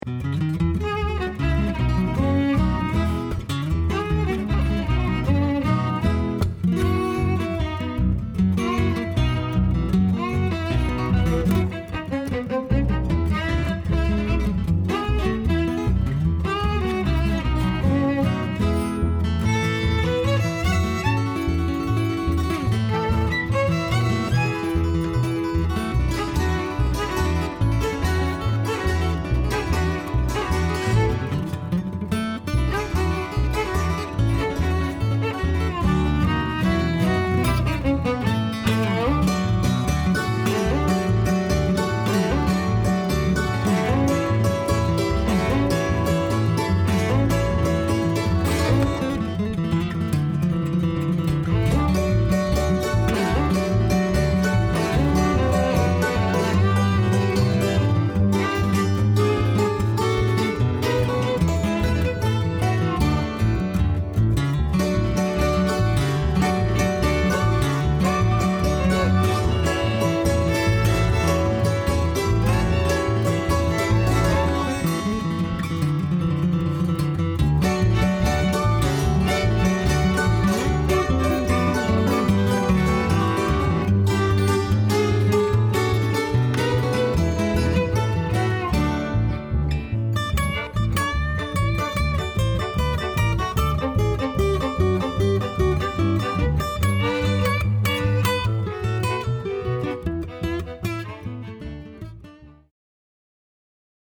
hammered dulcimer & vocals
guitar, banjo, background vocals
bass, keyboard
fiddle & mandolin